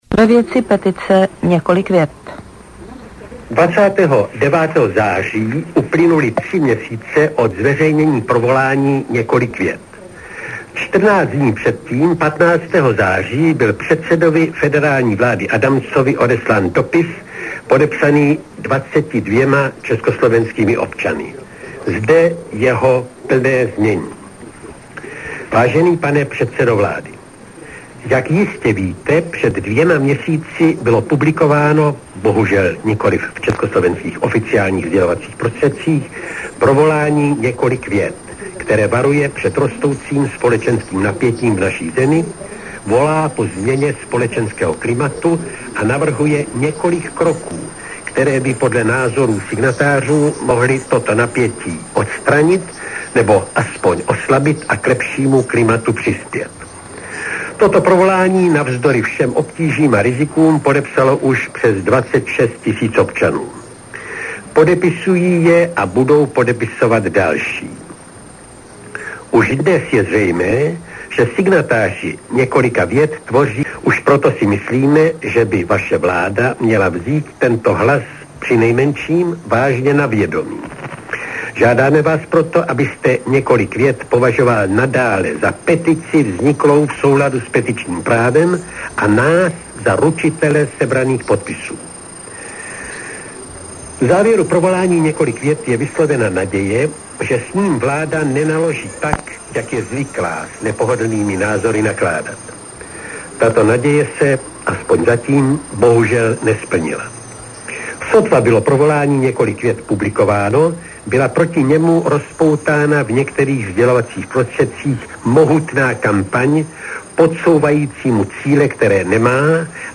Z vysielania Hlasu Ameriky a Rádia Slobodná Európa  (august – november 1989)    August 1989